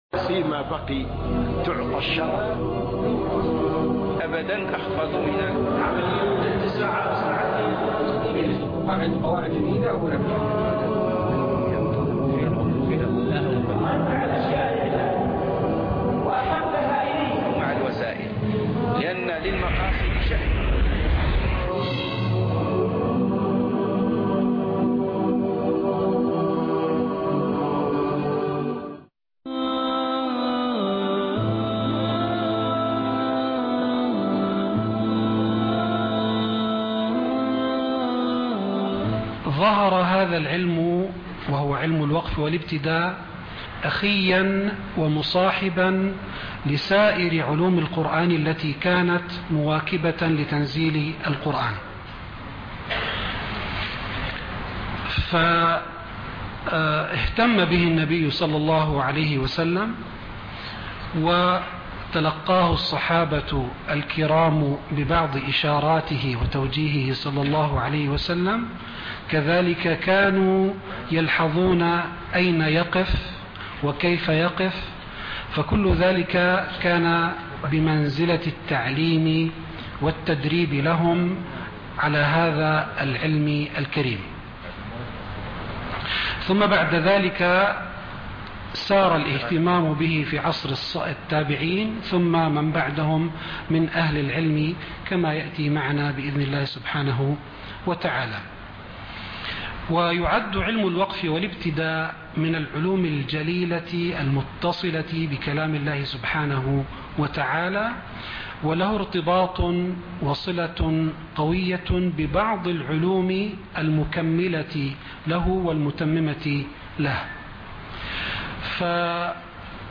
دورة الوقف والإبتداء (الجزء الأول) (20/11/2013) المؤتمر العالمي الثاني لتعليم القرآن الكريم - قسم المنوعات